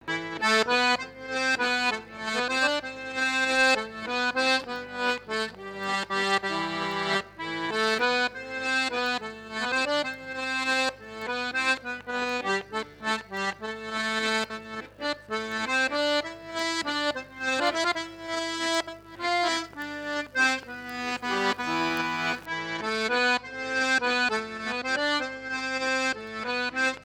danse : valse
Fête de l'accordéon
Pièce musicale inédite